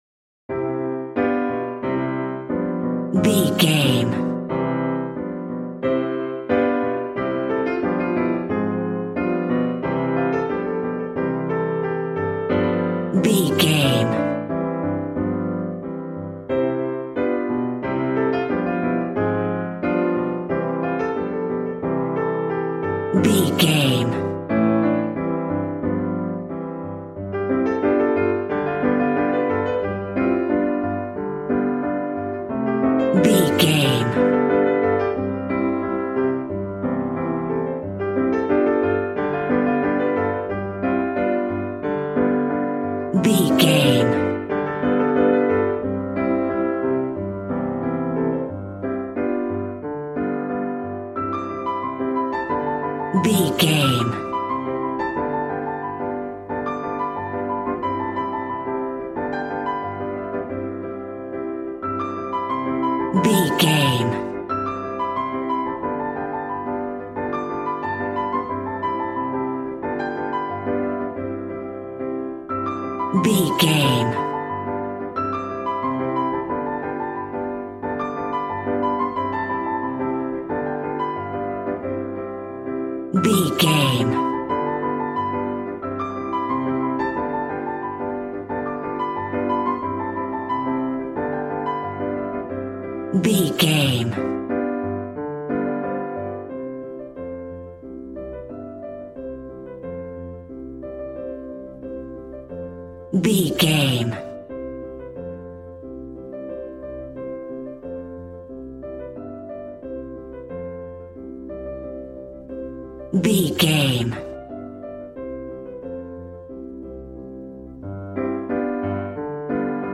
Ionian/Major
passionate
acoustic guitar